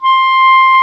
WND  CLAR 0M.wav